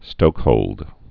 (stōkhōld)